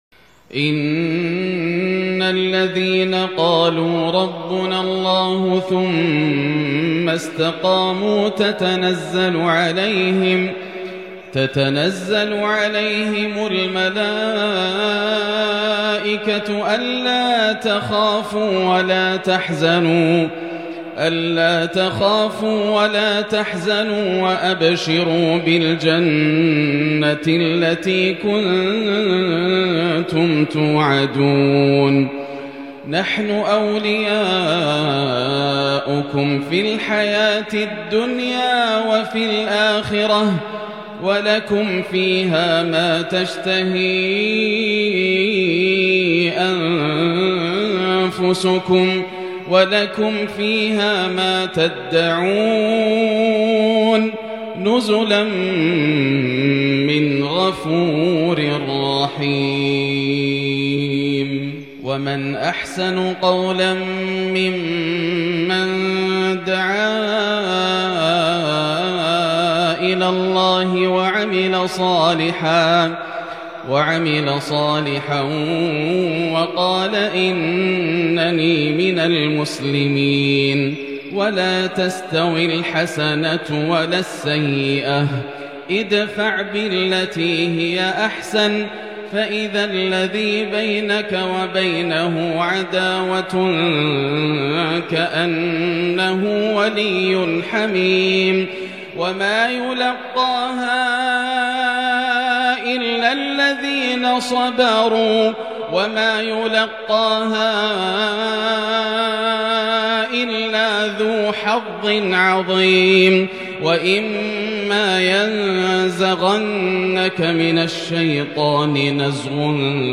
مغرب الجمعة 2-6-1442 هـ من سورة فصلت | Maghrib prayer from Surat Fussilat 15/1/2021 > 1442 🕋 > الفروض - تلاوات الحرمين